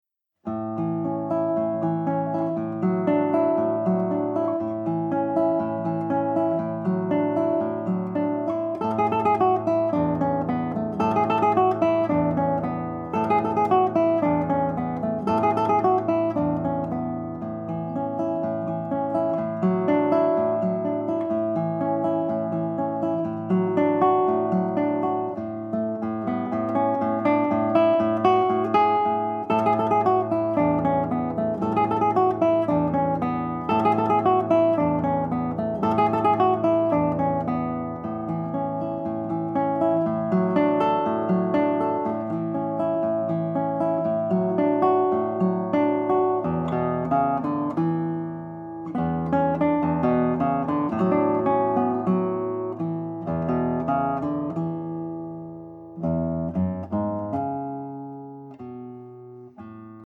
Collezione di studi per chitarra
chitarrista